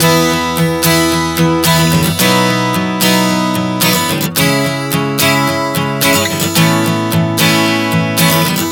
Prog 110 E-B-C#m-A.wav